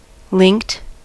linked: Wikimedia Commons US English Pronunciations
En-us-linked.WAV